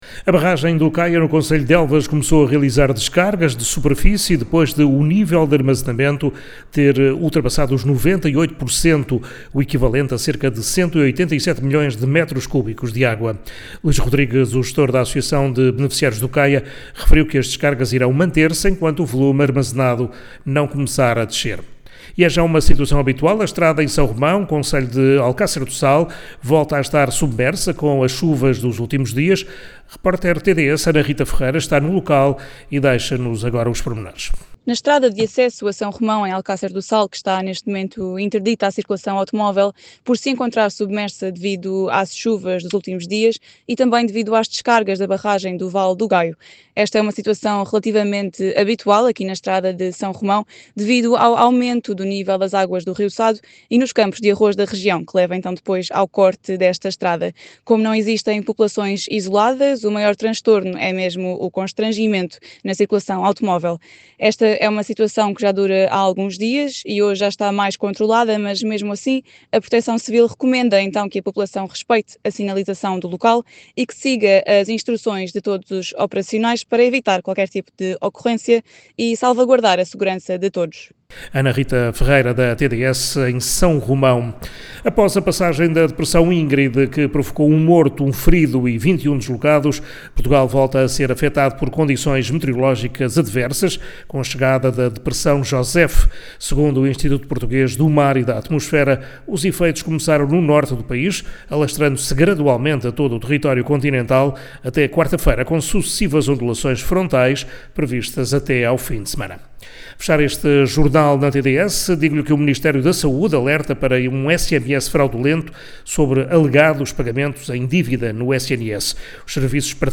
Reportagem em S.Romão do Sado